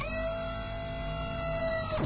GunLower2.ogg